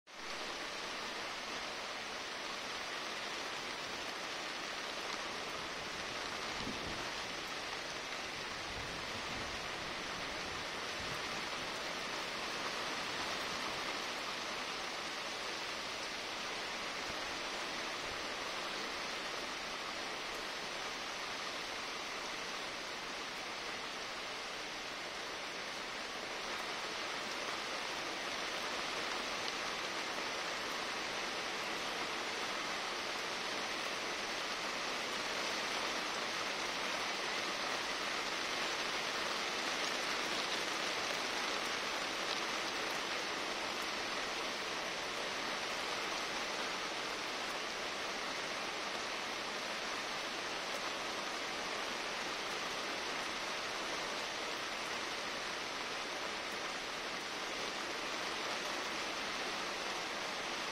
Muhteşem yağmur sesi 1 dakikalık kayıt.mp3